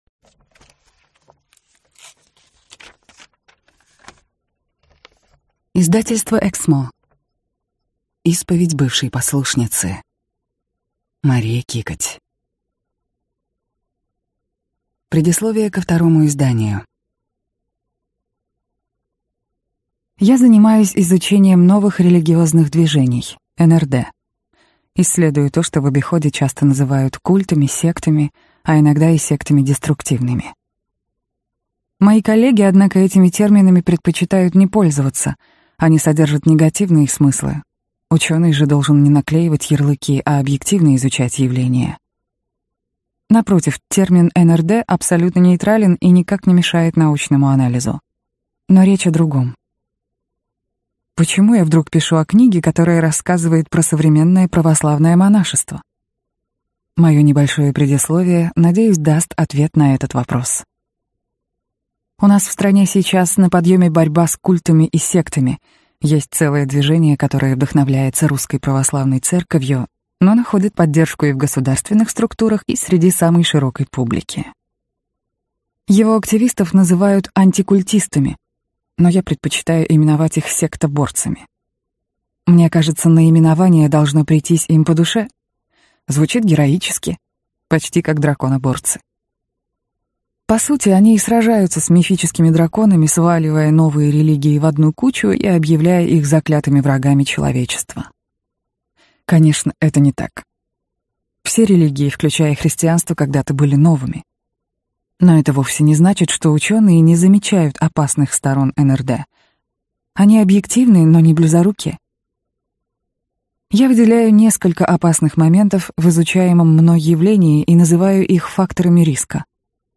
Аудиокнига Исповедь бывшей послушницы | Библиотека аудиокниг
Прослушать и бесплатно скачать фрагмент аудиокниги